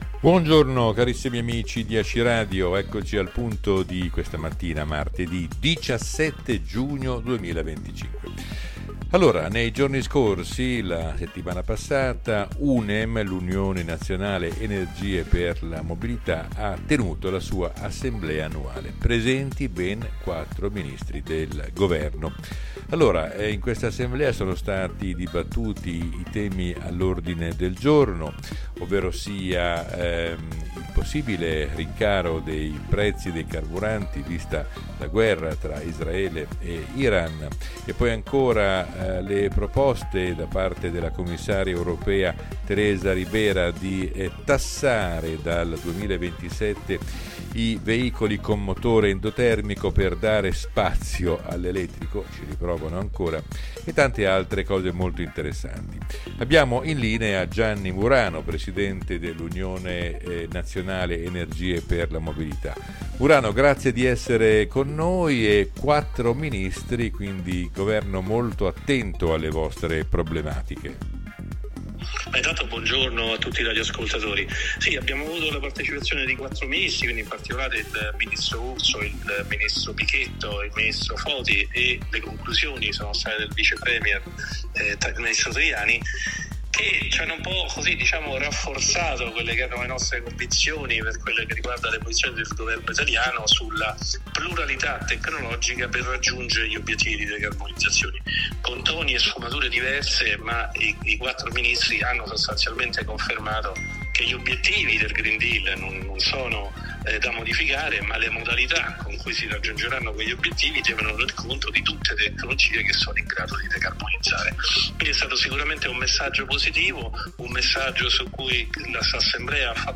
Assemblea UNEM 2025